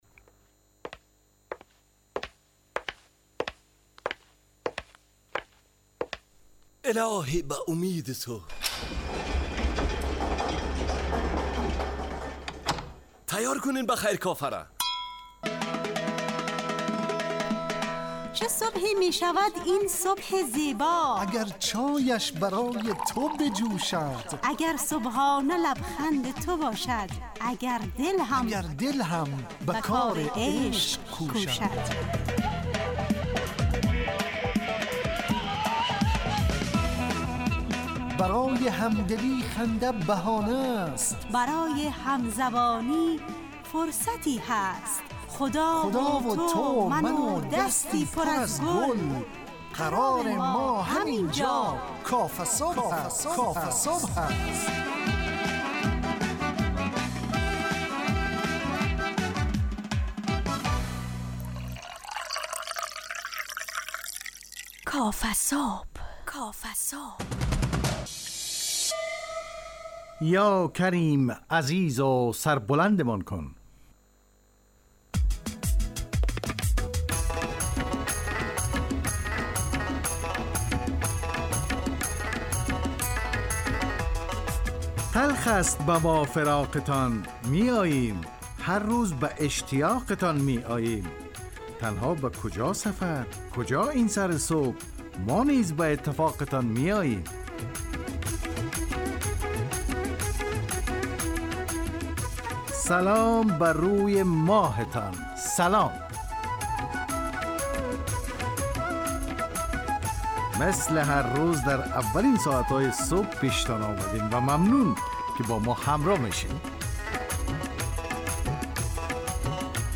کافه‌صبح – مجله‌ی صبحگاهی رادیو دری با هدف ایجاد فضای شاد و پرنشاط صبحگاهی همراه با طرح موضوعات اجتماعی، فرهنگی و اقتصادی جامعه افغانستان با بخش‌های کارشناسی، هواشناسی، نگاهی به سایت‌ها، گزارش، گپ صبح، صبح جامعه و صداها و پیام‌ها شنونده‌های عزیز